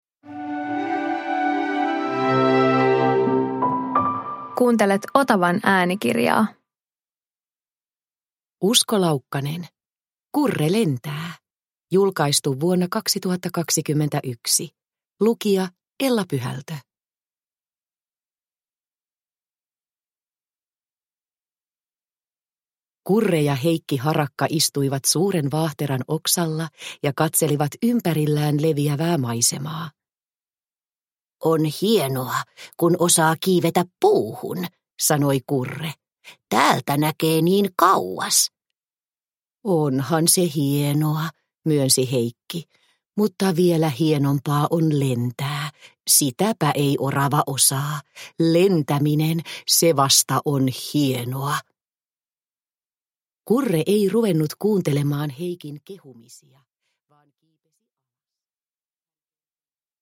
Kurre lentää – Ljudbok – Laddas ner